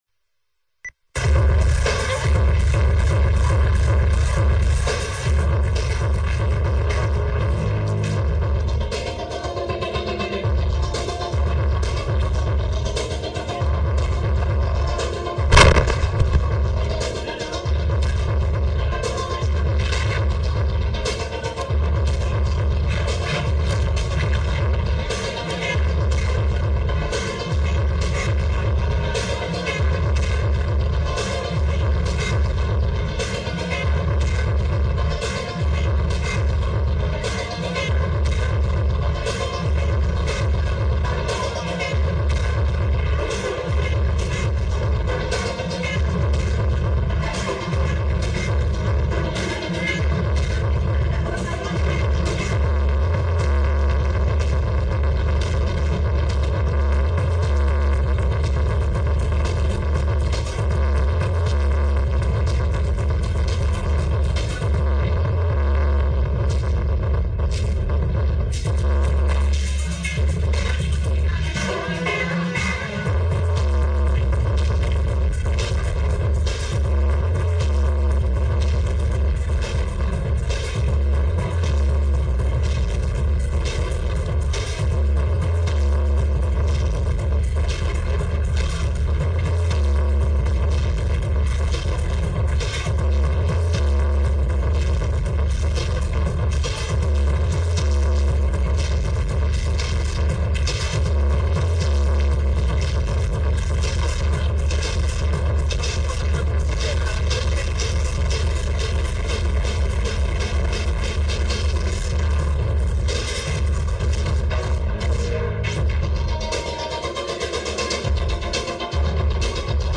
Uk venue HMS President